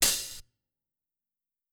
drum-hitwhistle.wav